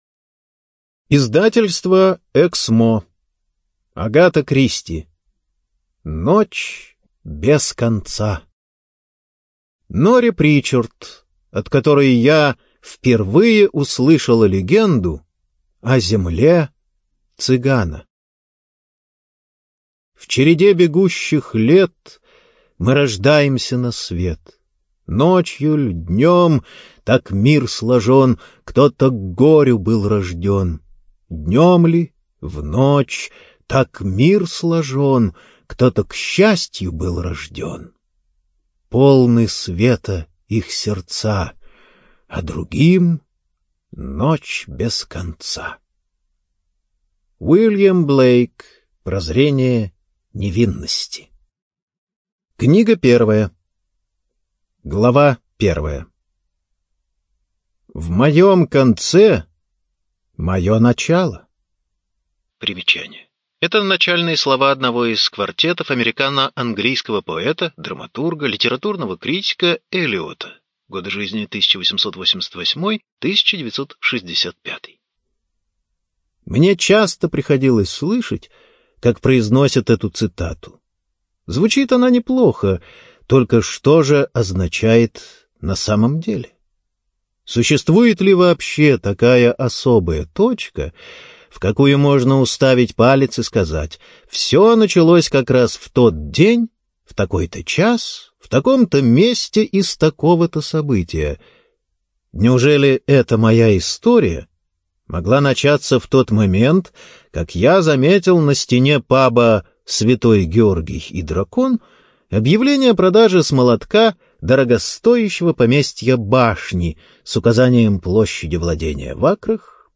Студия звукозаписиЭКСМО